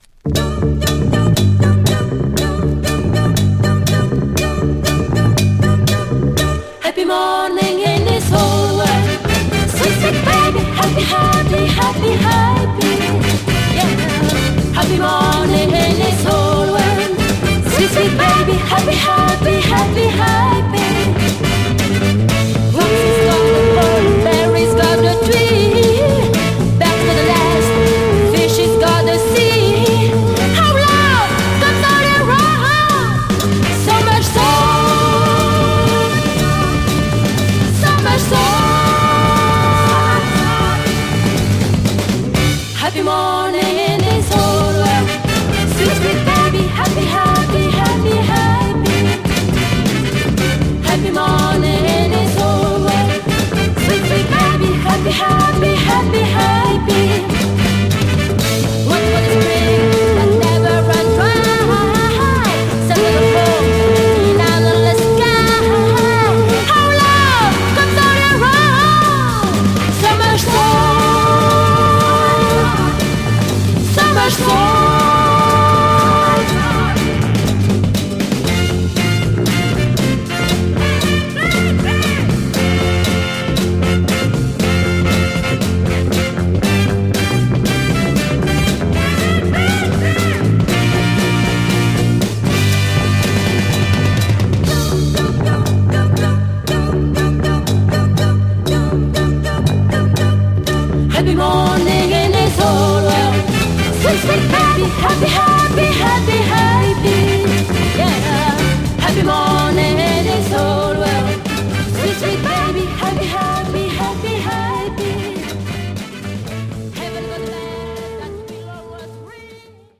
Northern Soul tunisie
厚みのあるバッキングに、姉妹のエネルギッシュなヴォーカルが弾けます♪